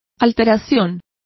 Complete with pronunciation of the translation of alteration.